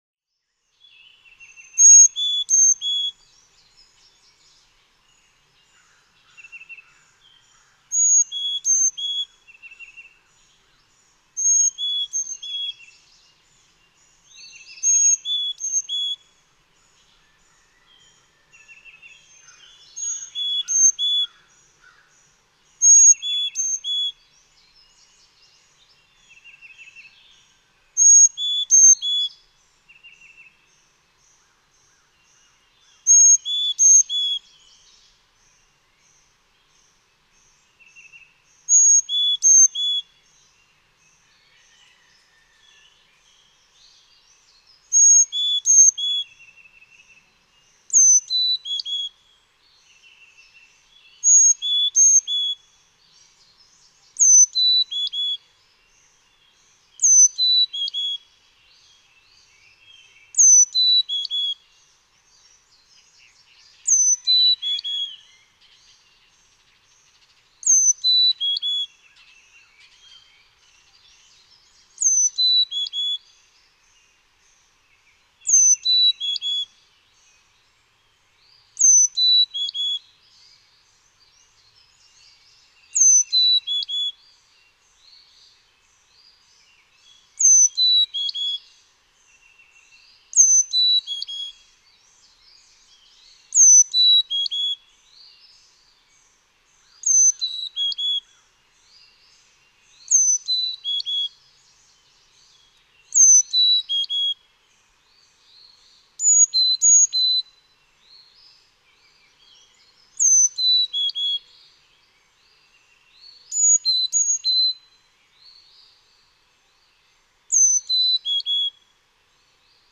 Carolina chickadee
During routine singing, a male typically repeats one of his songs several times before switching to another; during the transition from one type to another, he may alternate the two songs.
Troutville, Virginia.
393_Carolina_Chickadee.mp3